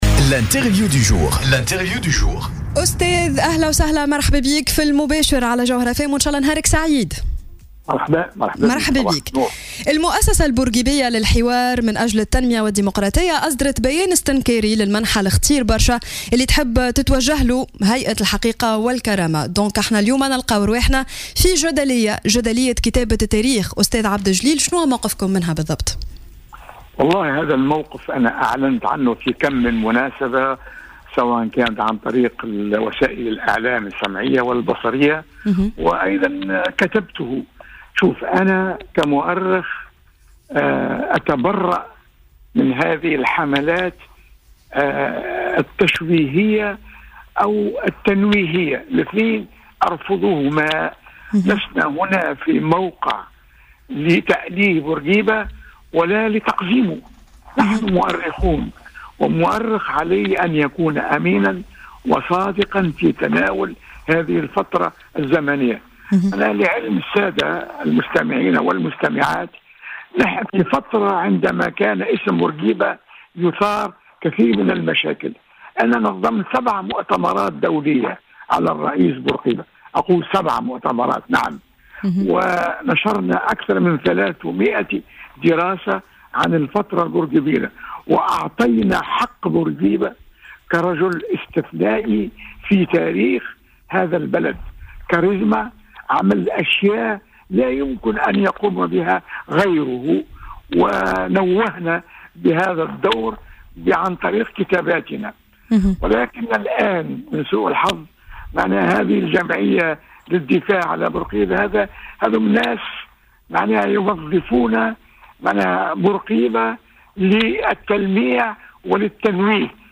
وكشف المؤرخ التونسي لـ "الجوهرة أف أم" في برنامج "صباح الورد" بعض الحقائق عن شخصية بورقيبة، منددا بتوظيف بعض السياسيين سيرة بورقيبة واسمه لأغراض حزبية وسياسية.